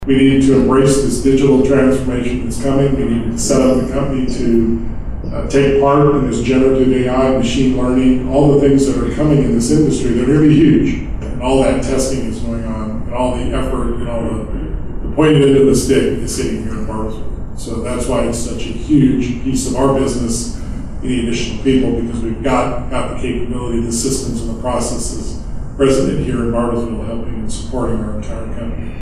Lance was the guest of honor for a Bartlesville Chamber of Commerce forum at Tri-County Tech on Tuesday.